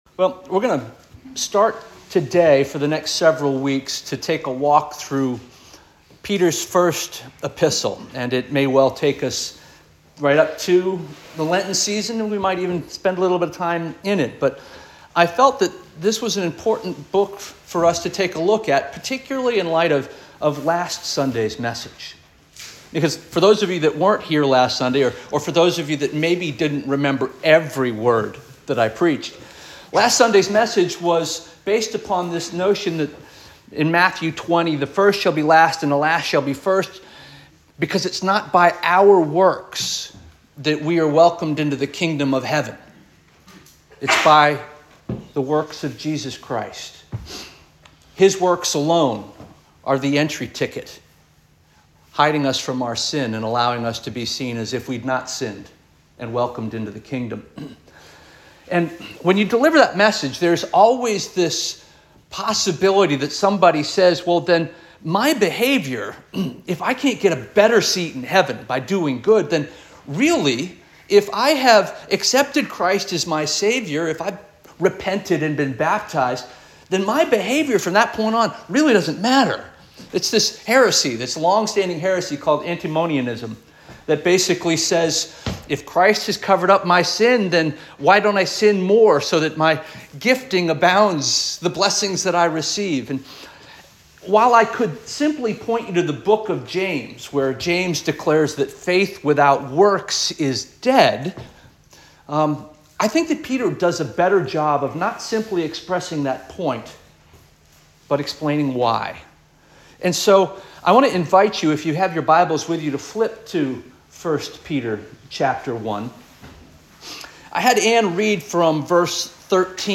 February 9 2025 Sermon - First Union African Baptist Church